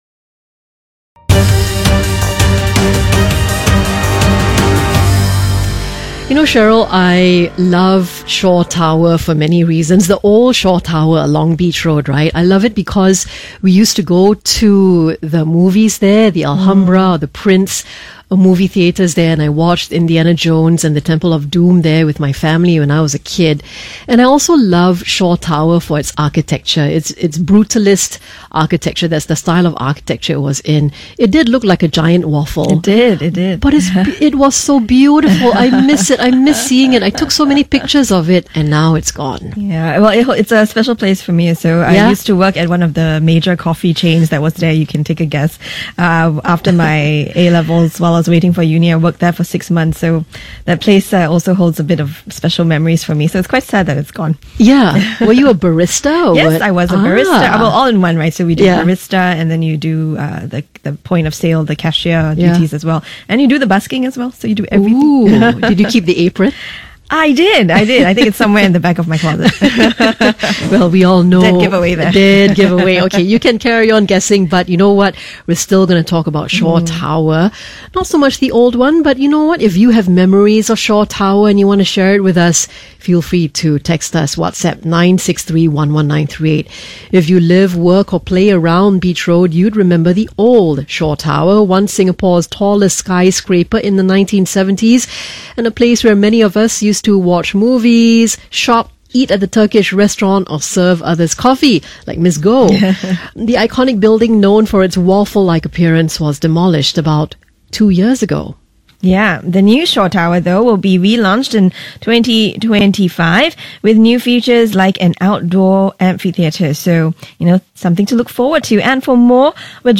CNA interview